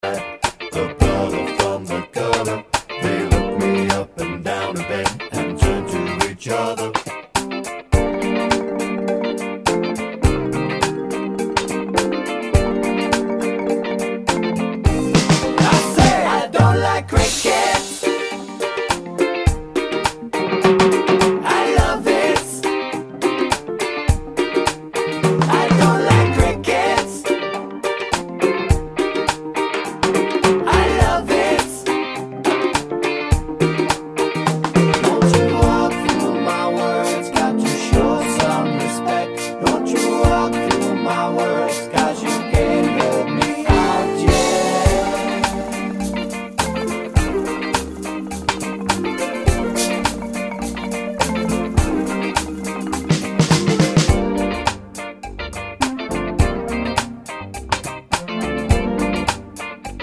Minus Bass Guitar Pop (1970s) 5:02 Buy £1.50